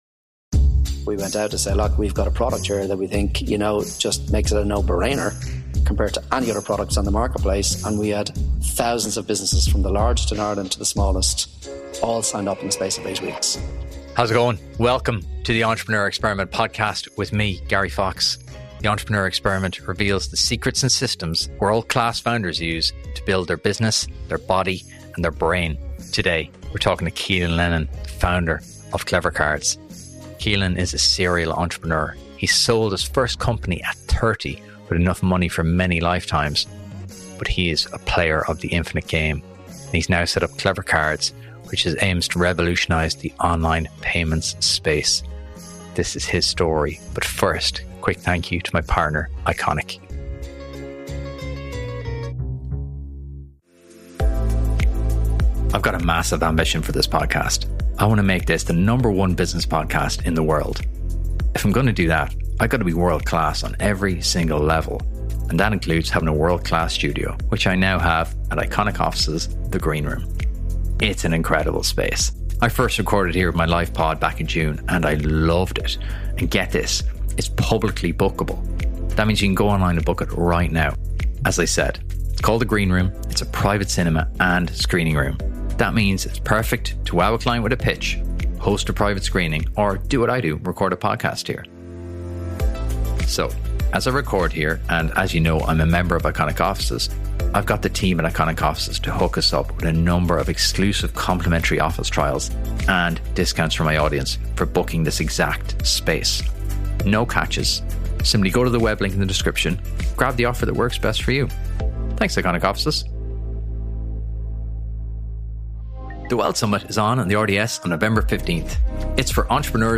In a wide-ranging interview